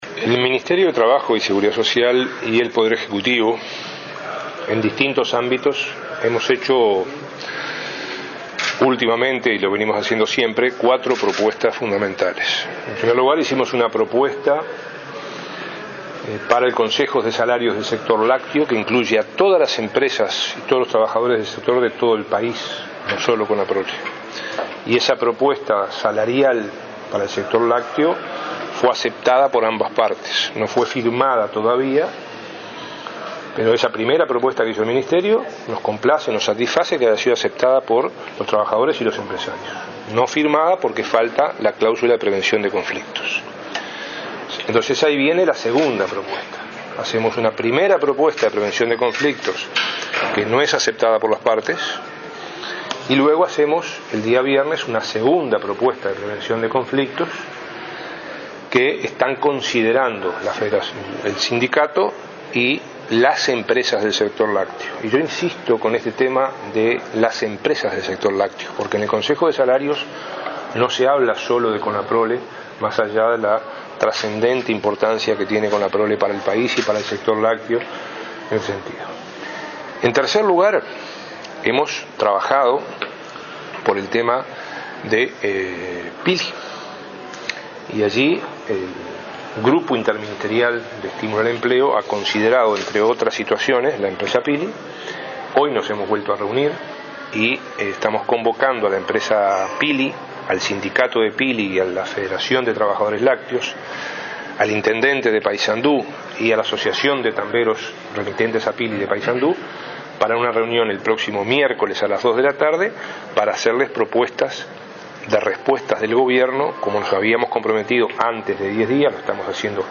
En la Comisión de Cooperativismo de Diputados, el ministro destacó las propuestas del Gobierno sobre salarios, prevención de conflictos, soluciones a la empresa Pili y la no obligatoriedad de reponer vacantes en Conaprole.